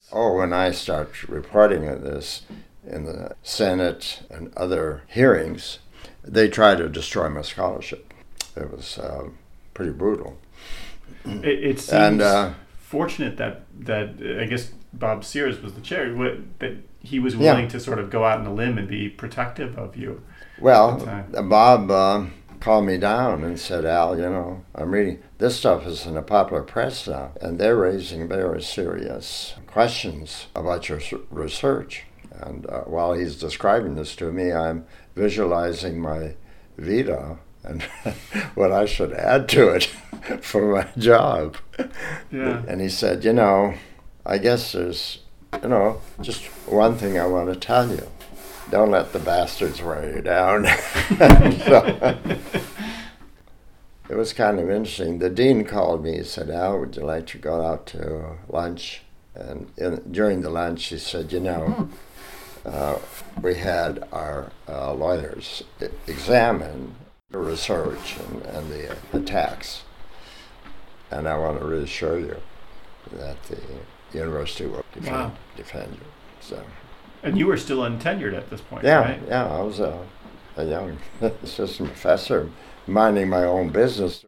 And so, it is with great pleasure that I share with you some tidbits, excerpts, and reflections from my conversations with Dr. Albert Bandura, the David Jordan Professor Emeritus of Social Science in Psychology at Stanford University.